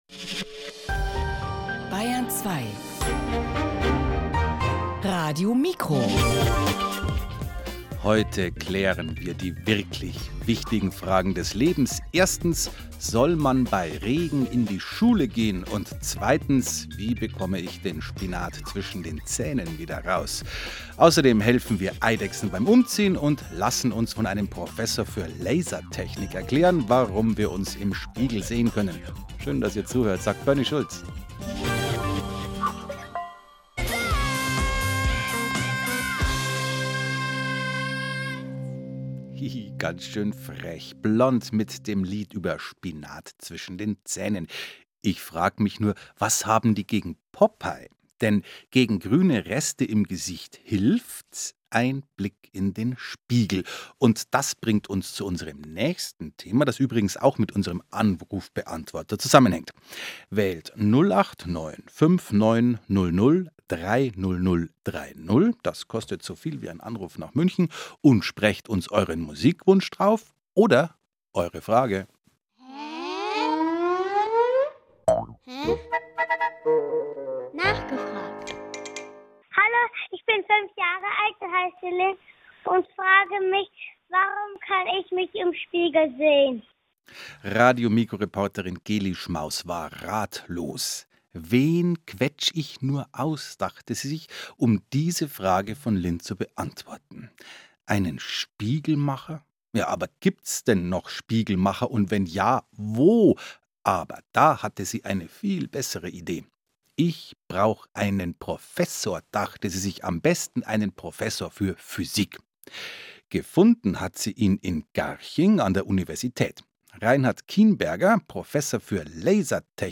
Interview at RadioMikro, BR Kinder (available only in German)